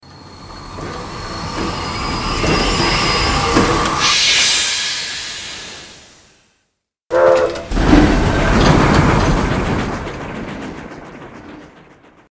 CBHQ_TRAIN_stopstart.ogg